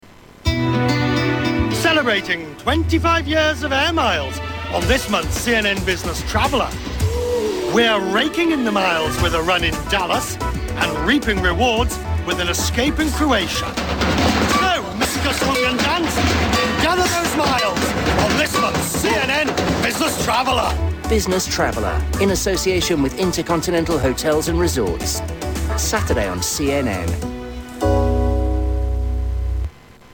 CNN Business Traveller Promo
Tags: Media Richard Quest News Reporter Business Traveller Richard Quest Audio clips